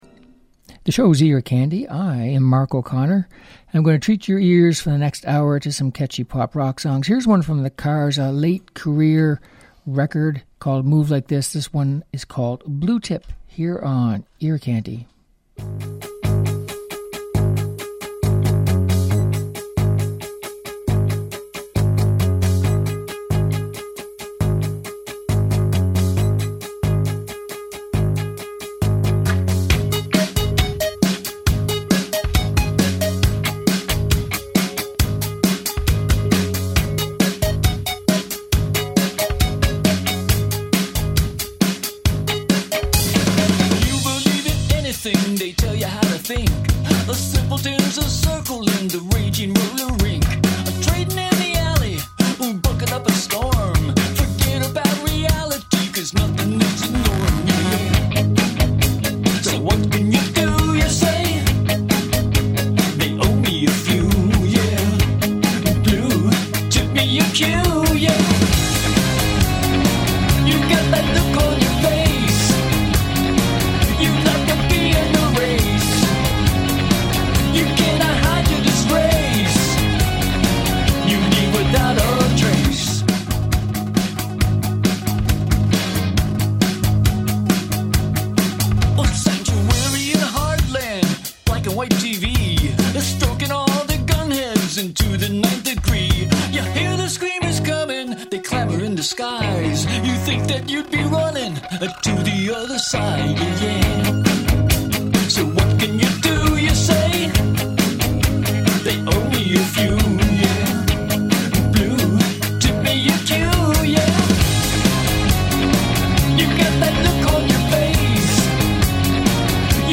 Upbeat Pop and Rock Songs